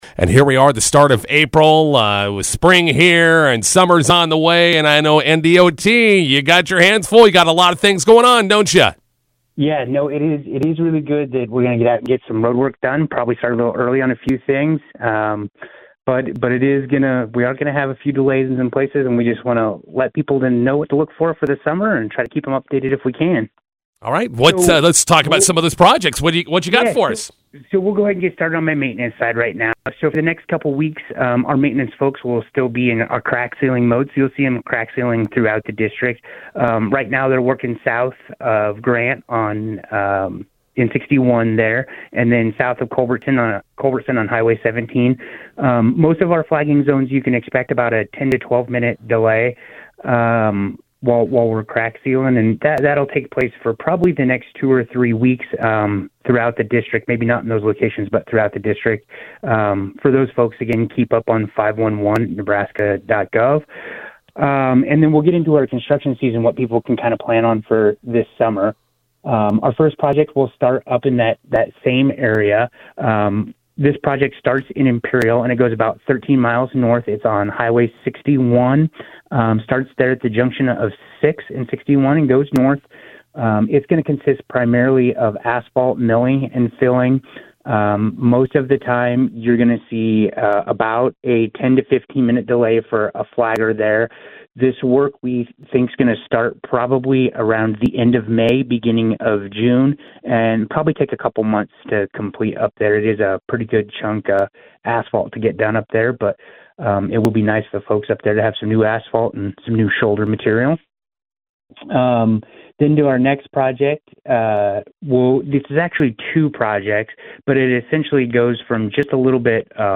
INTERVIEW: Nebraska Department of Transportation spring/summer projects underway.